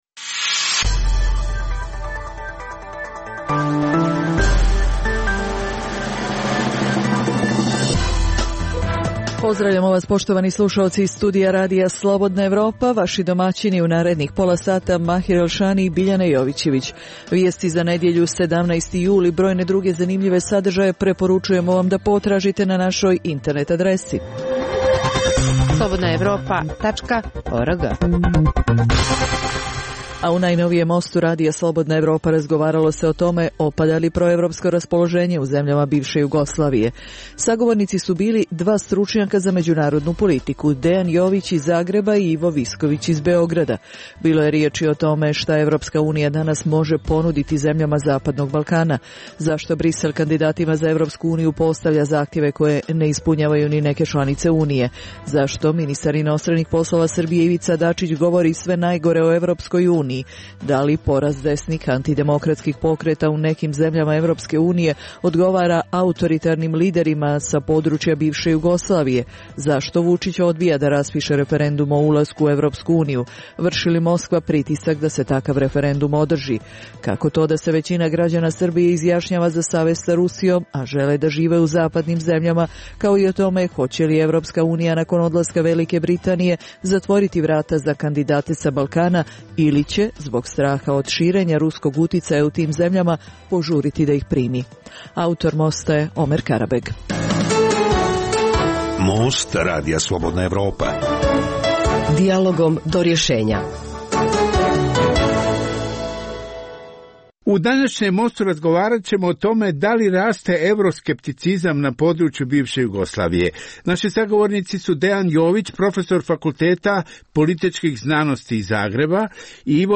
u kojem ugledni sagovornici iz regiona razmtraju aktuelne teme. Drugi dio emisije čini program "Pred licem pravde" o suđenjima za ratne zločine na prostoru bivše Jugoslavije.